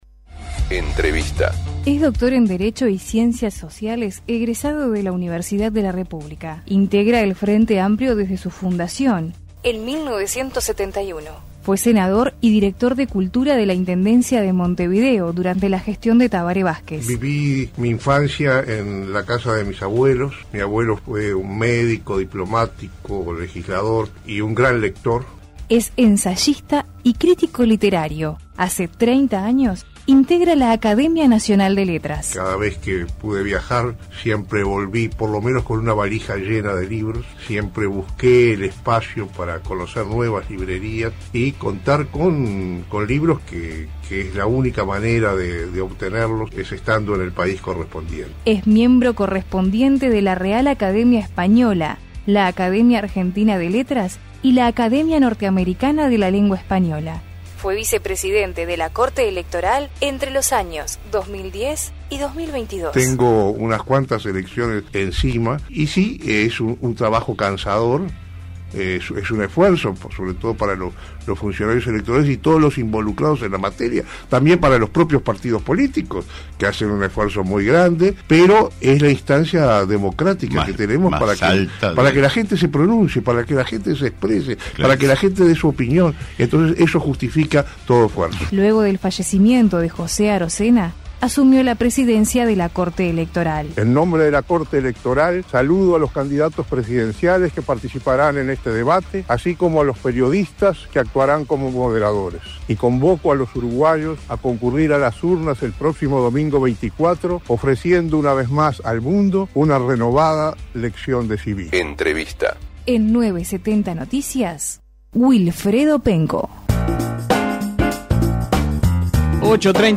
El presidente de la Corte Electoral, Wilfredo Penco, en diálogo con 970 Noticias criticó la ley 16.019, Actos Comiciales, Propaganda Proselitista, Veda Electoral.